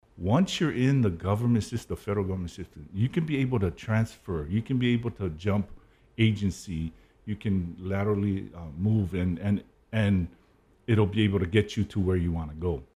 Ahead of the Federal Pathways Career fair that is happening today at the National Weather Service Compound, KHJ’s morning show had some federal employees in the studio to promote the event.